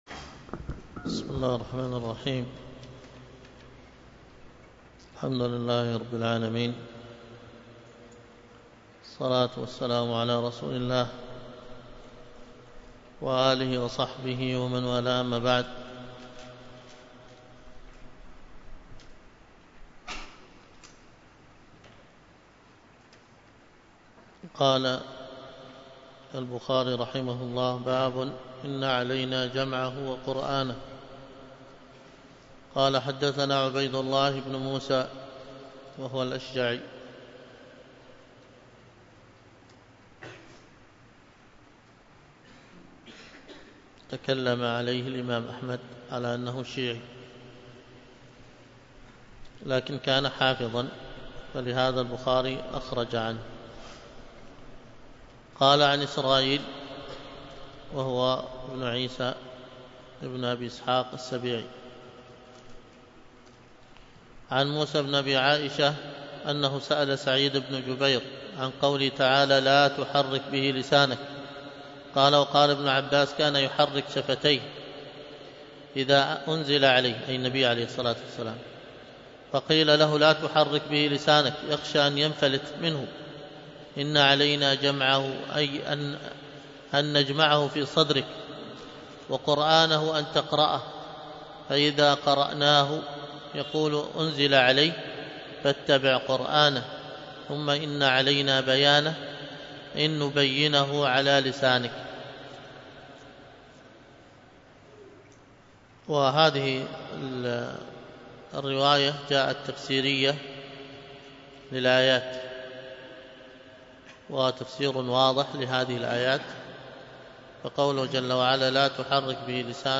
الدرس في شرح السنة للمزني 2، الدرس الثاني:من( والإيمان قول وعمل وهما سيان ونظامان وقرينان ... كما بدأه لهم من شقاوة وسعادة يومئذ يعودون فريق في الجنة وفريق في السعير ).